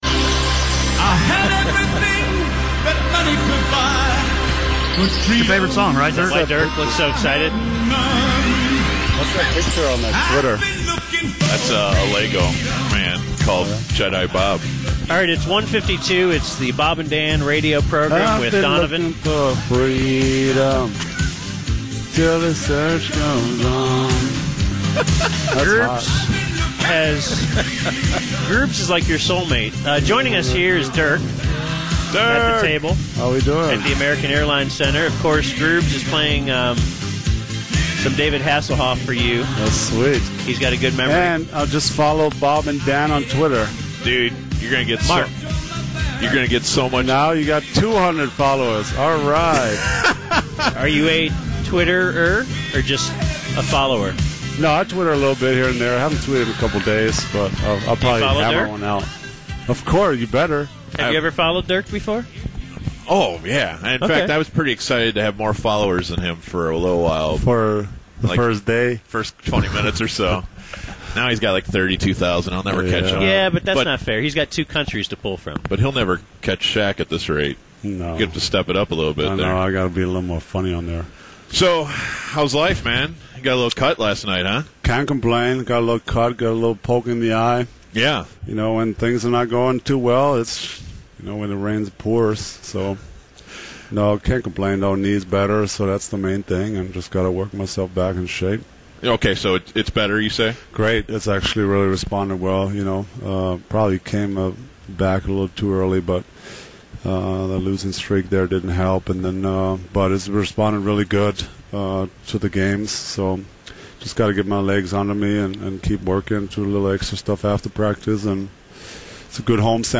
Once a year, BaD Radio has an interview with the German of all Germans, one of my favorite ballers, Dirk Nowitzki.
BaD-Dirk-Interview.mp3